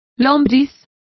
Complete with pronunciation of the translation of earthworm.